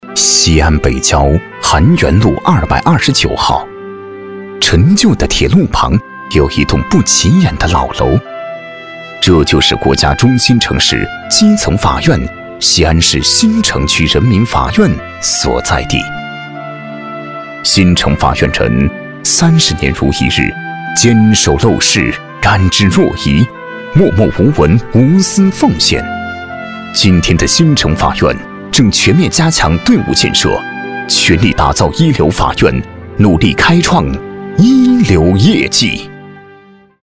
激情力度 企业专题,人物专题,医疗专题,学校专题,产品解说,警示教育,规划总结配音
大气男音，激情震撼。